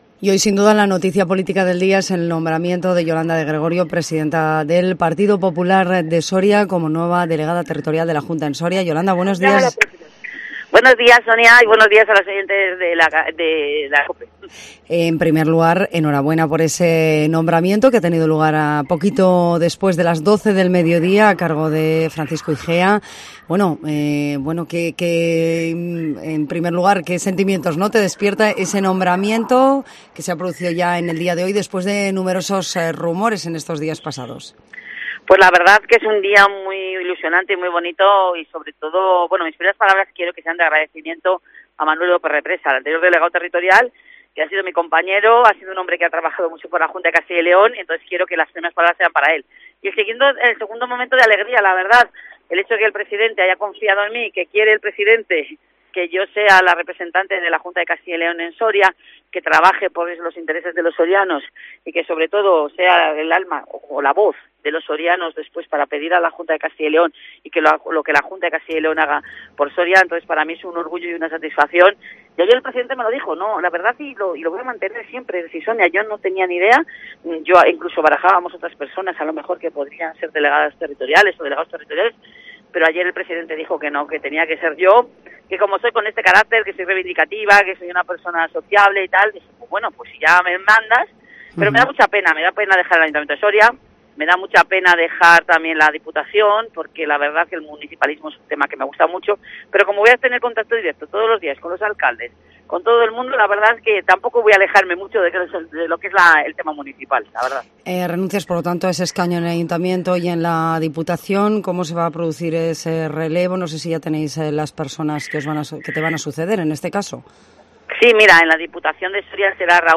Entrevista en Cope Soria a la nueva delegada territorial de la Junta en Soria